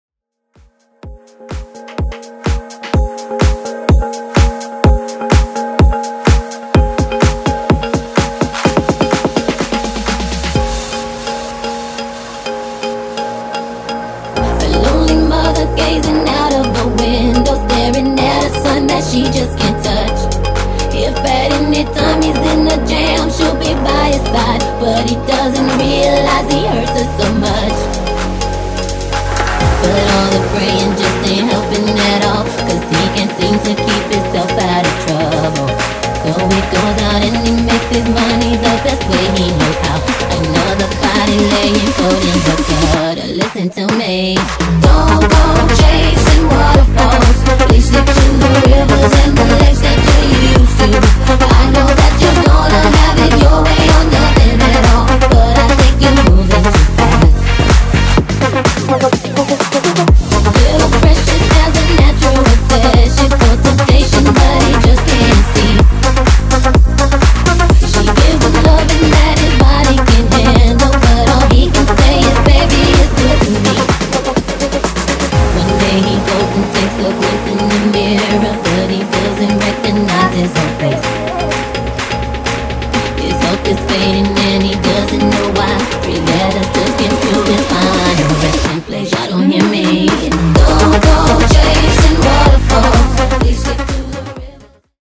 Genre: AFROBEAT
Clean BPM: 132 Time